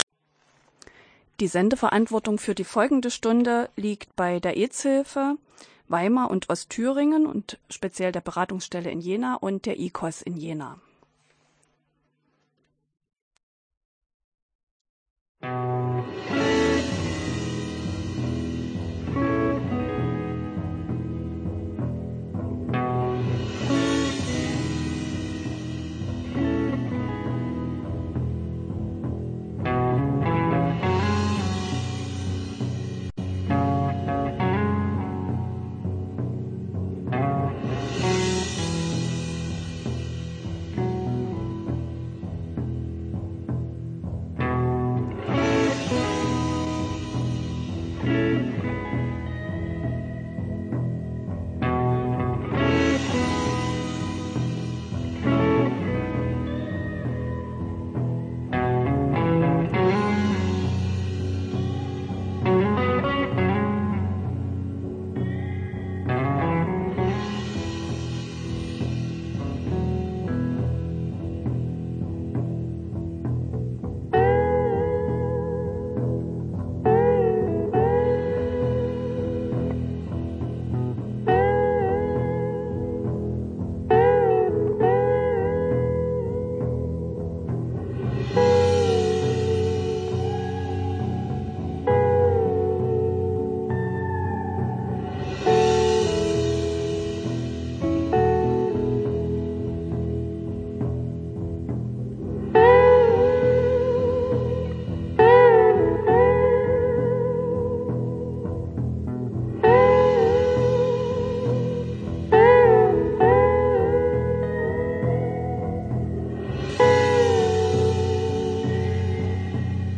Die AIDS-Hilfe Weimar & Ostthüringen sendet zusammen mit der Hochschulgruppe Queer-Paradies, dem Aufklärungsprojekt miteinAnderS und dem Verein QueerWeg aus dem Radio OKJ. Vorstellung der Organisationen und ihrer Projekte.
Download des Sendungs-Mitschnitts